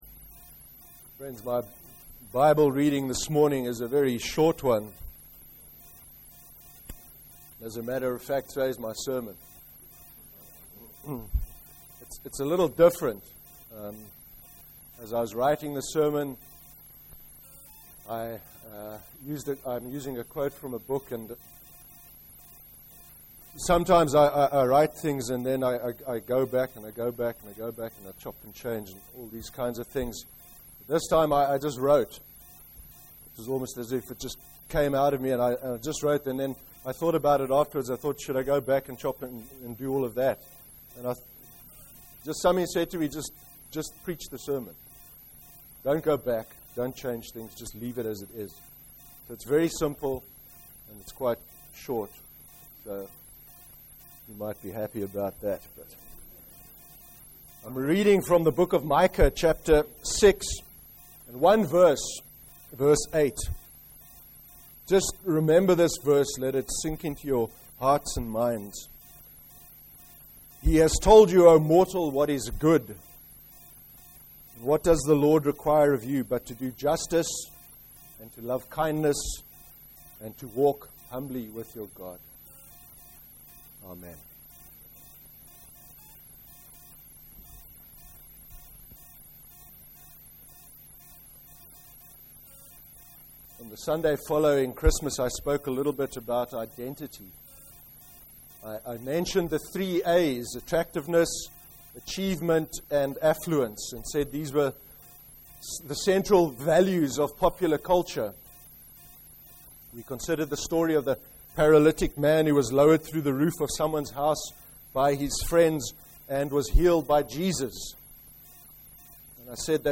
13/01/13 sermon – And what does the Lord require of you? (Micah 6:8)